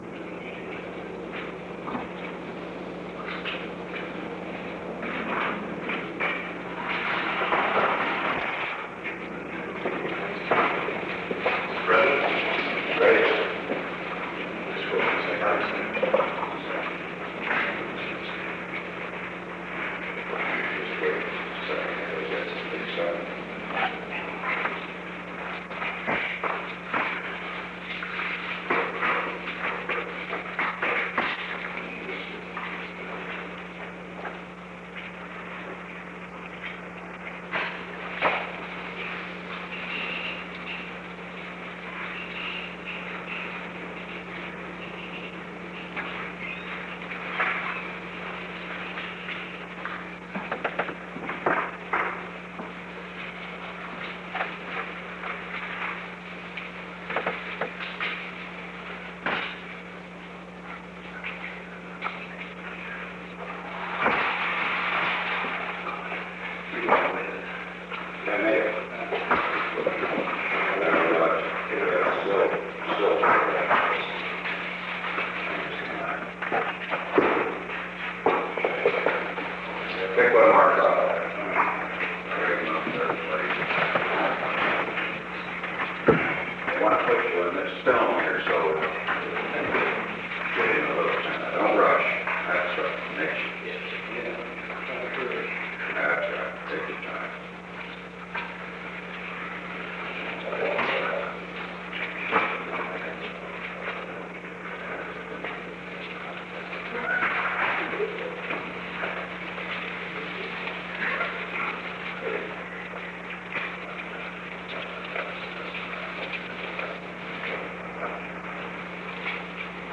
The Oval Office taping system captured this recording, which is known as Conversation 630-013 of the White House Tapes.